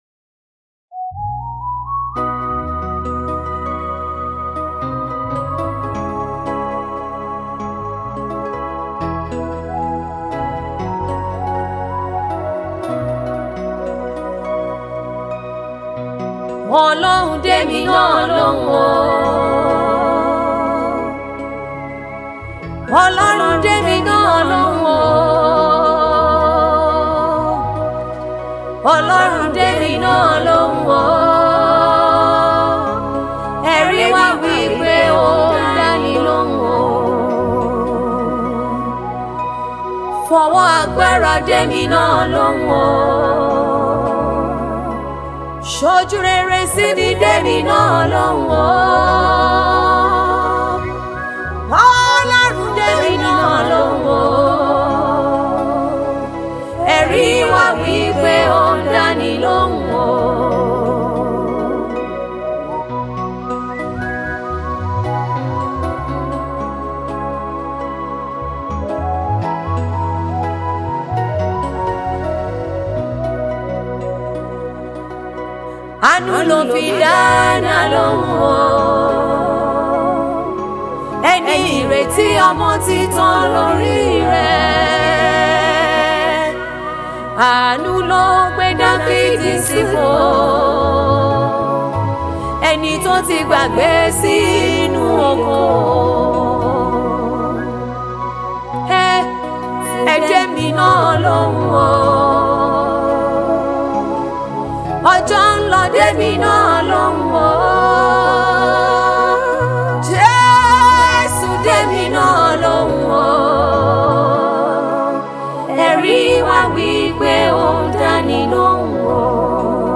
gospel music
prayer song